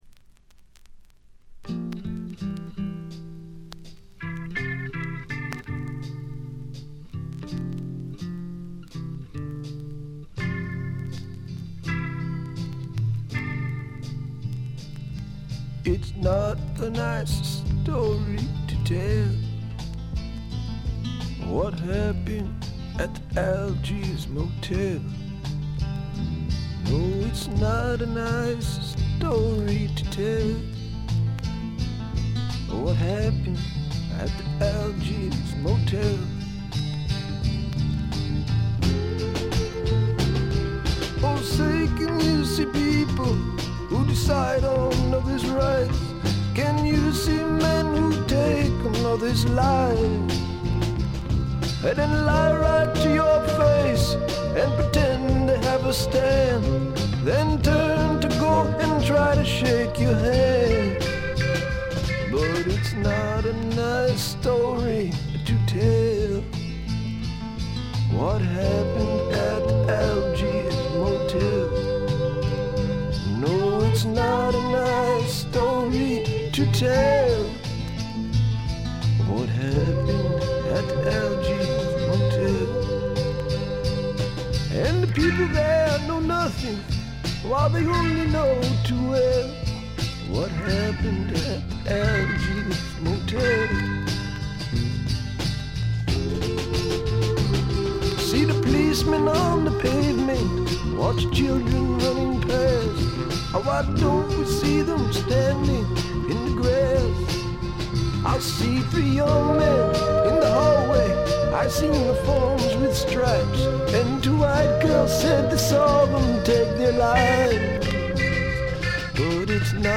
静音部（ほとんどないけど）でチリプチ少々、散発的なプツ音が2-3回出たかな？って程度。
試聴曲は現品からの取り込み音源です。